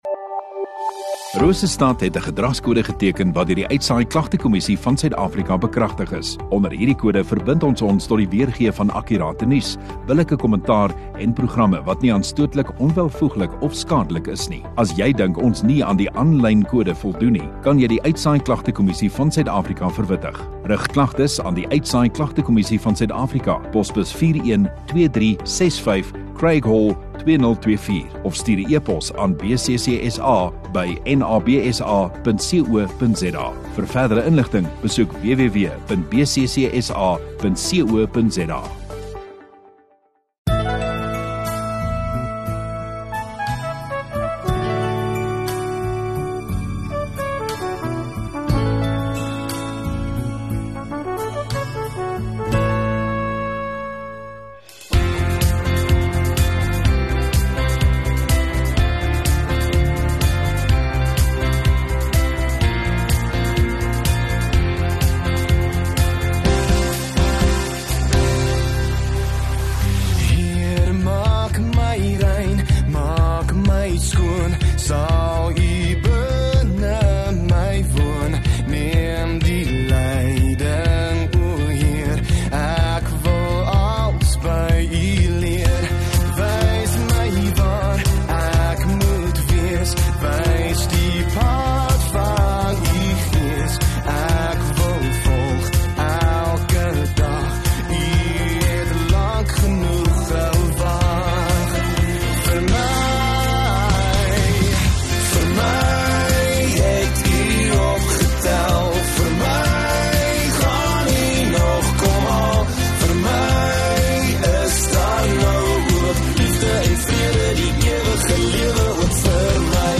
20 Apr Saterdag Oggendddiens